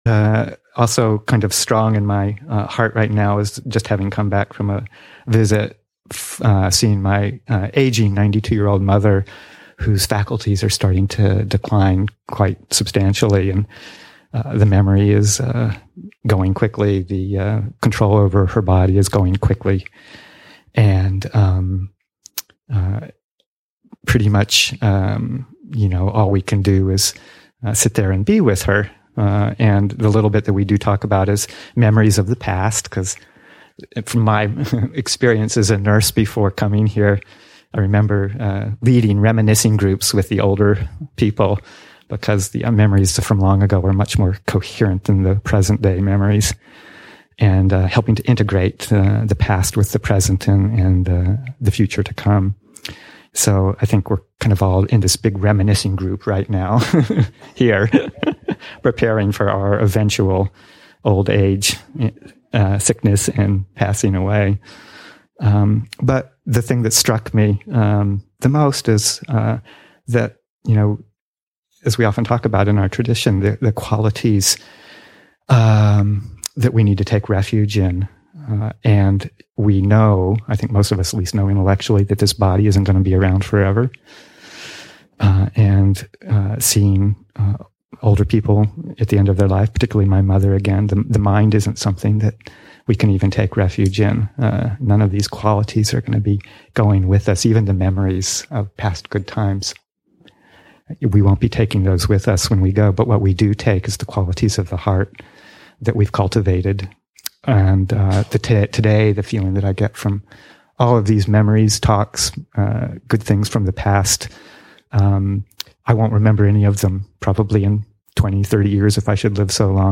Reflection